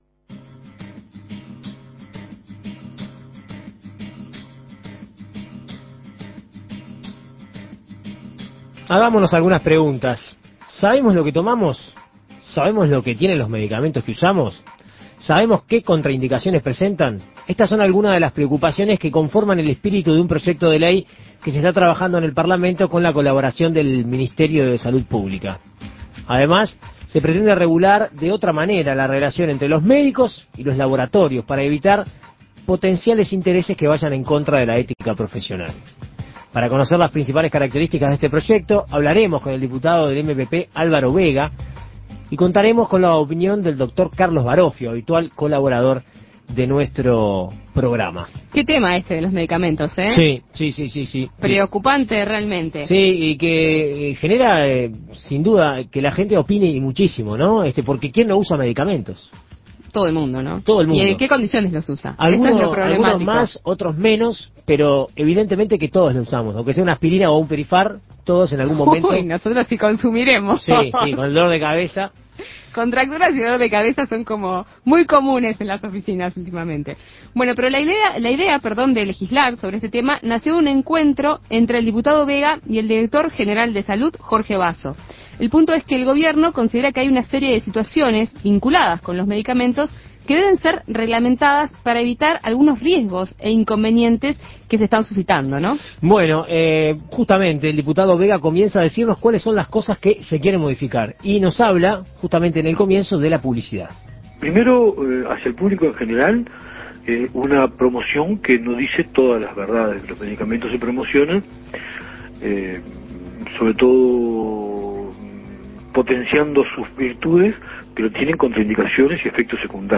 Informes